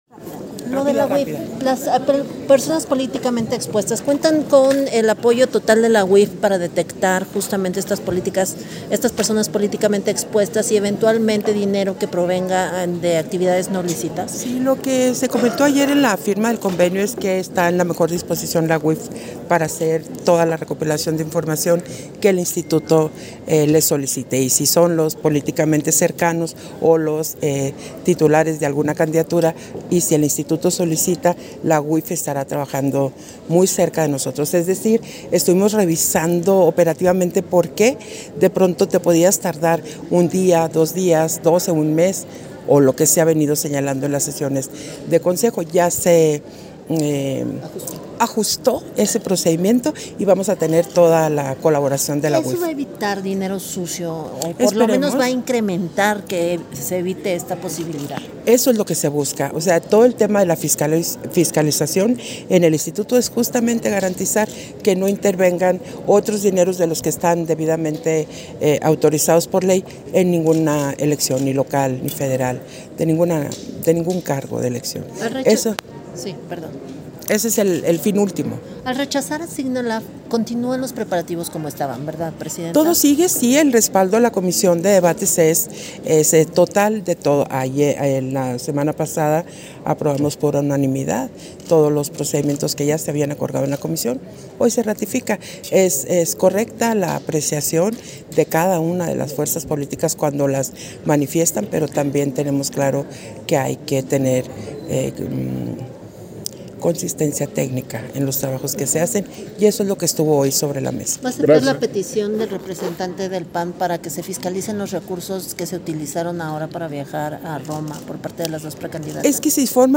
Audio de la entrevista a Guadalupe Taddei, posterior a la Sesión Extraordinaria del Consejo General